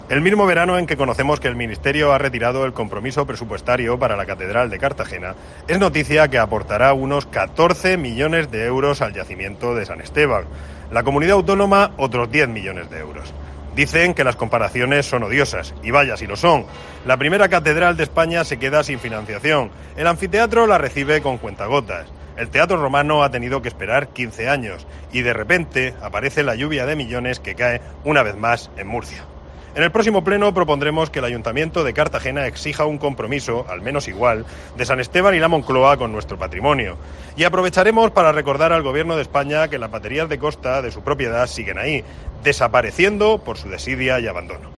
Jesús Giménez Gallo sobre inversión estatal y autonómica en el patrimonio histórico